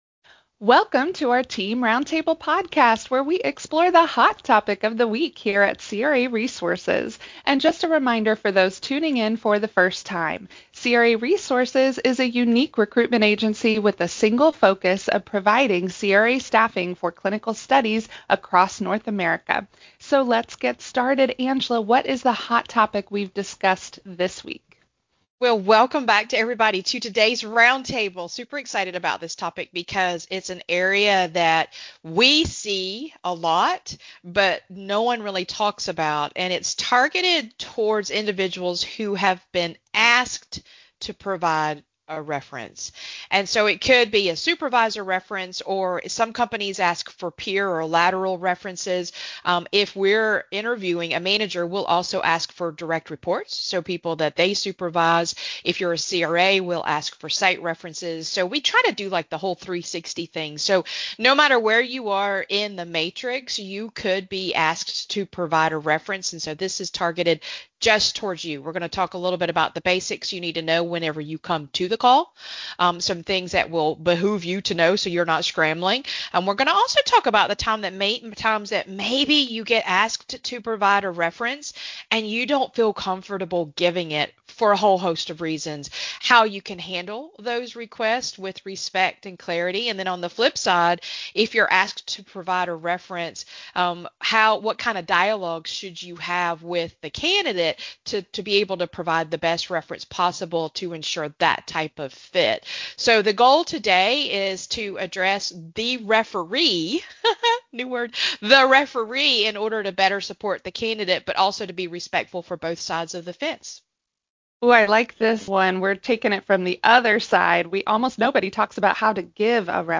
In this candid discussion, our panel shares what really happens behind the scenes of reference checks. We address why accuracy matters, how honesty protects all parties, and what information you, as the reference, will want to have ready before the call.